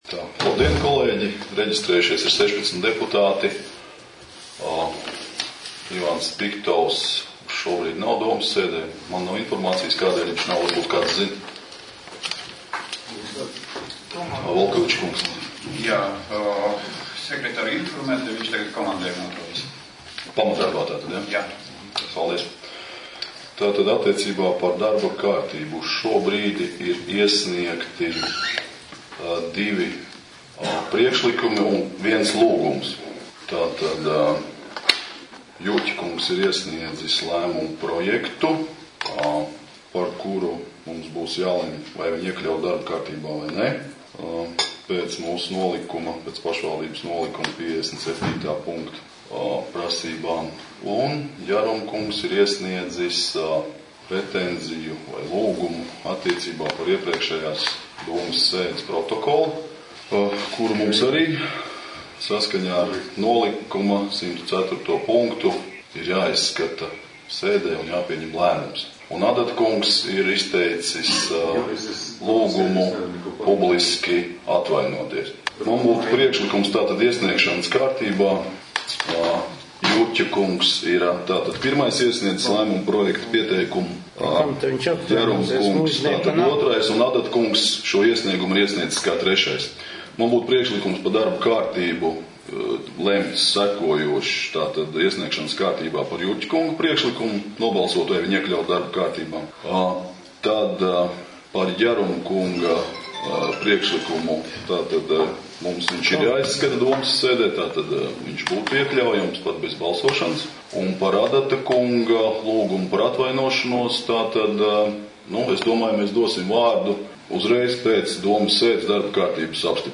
12.02.2015 Domes sēde Sēdes darba kārtība.